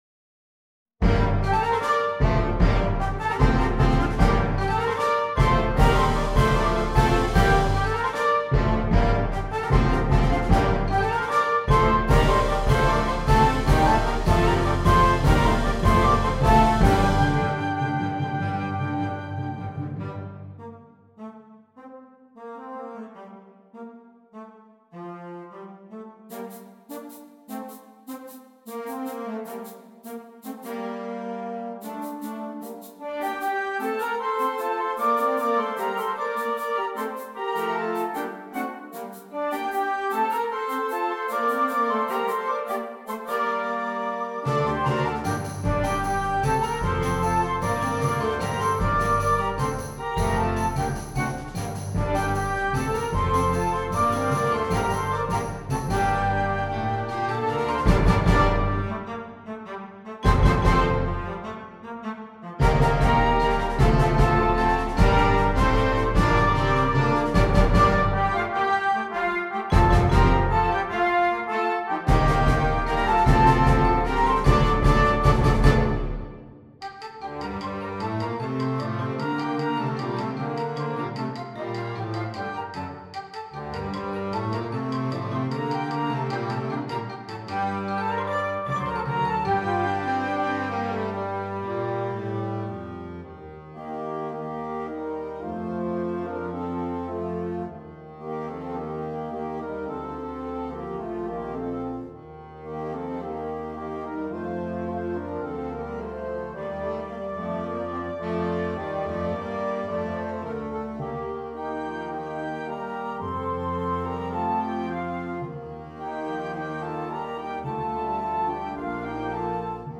Voicing: Flex Band